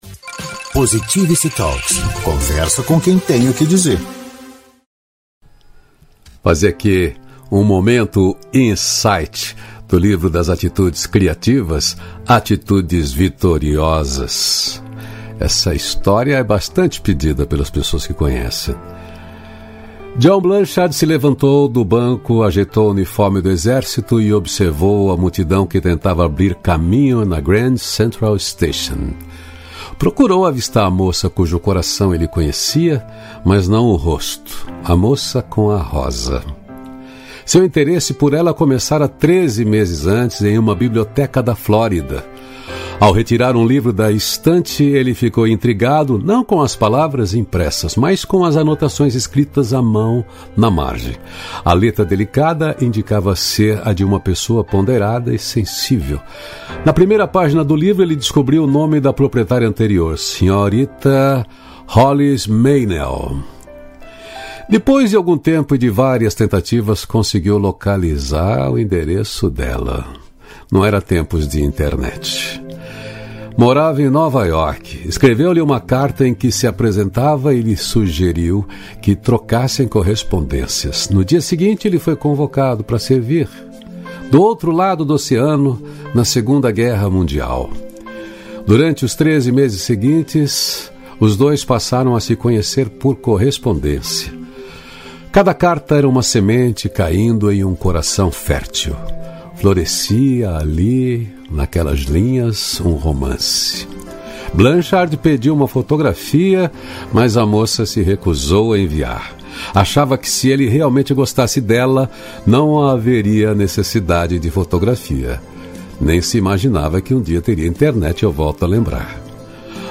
Momento Insight Ao Vivo